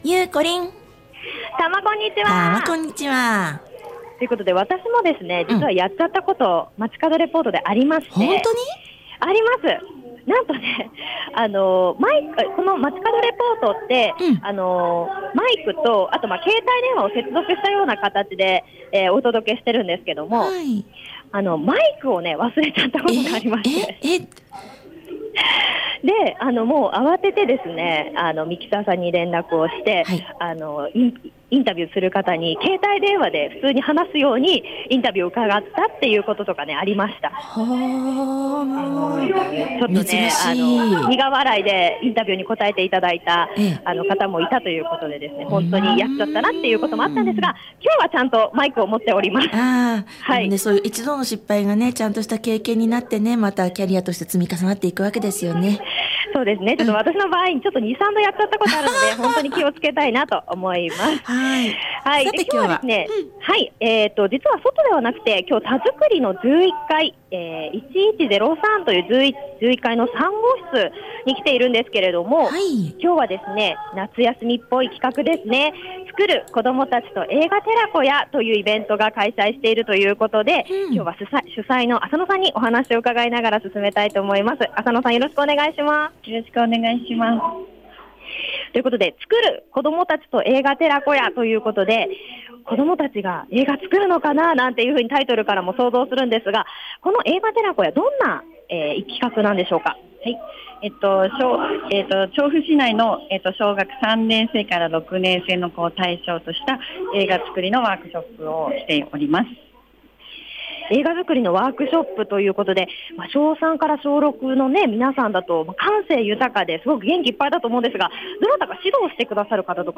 今日はたづくり11階で行われております、「つくる」子どもたちと映画寺子屋の4日目、映画の編集作業と上映会の宣伝物制作をしているところにお邪魔してきました★